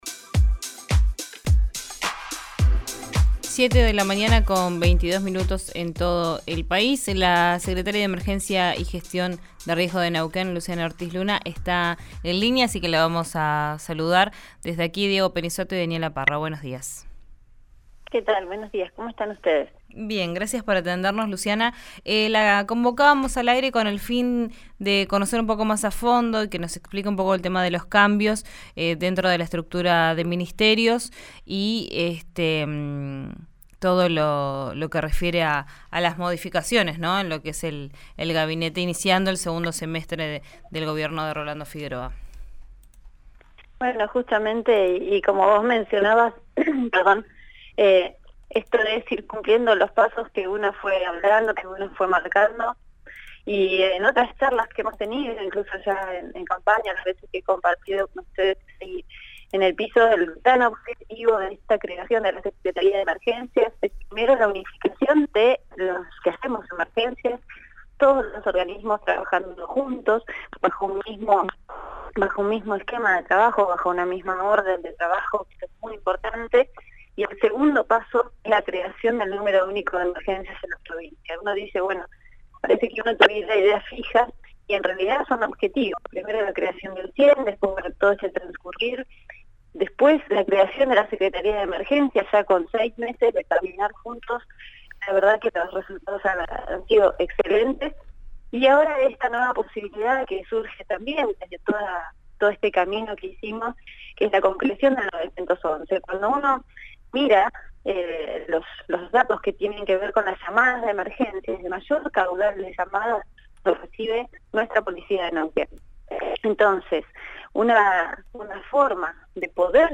Escuchá a Luciana Ortiz Luna, secretaria de Emergencias y Gestión de Riesgos, en RÍO NEGRO RADIO: